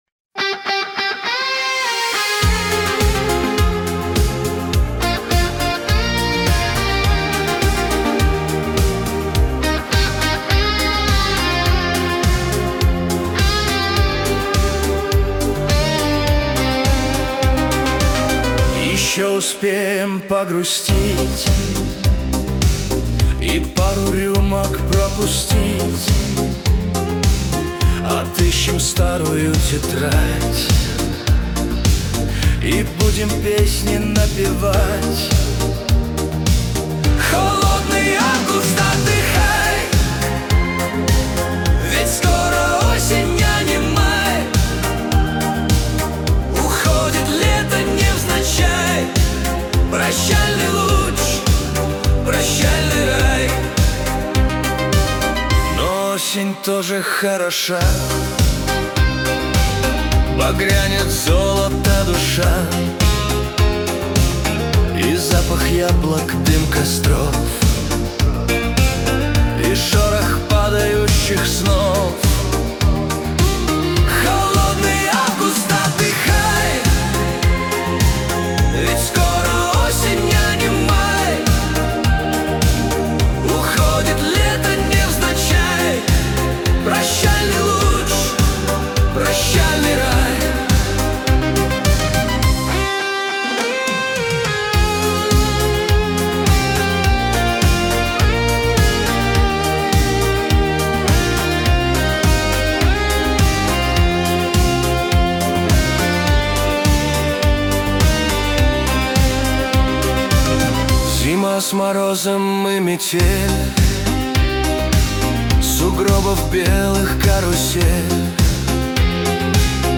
Поп музыка, Новинки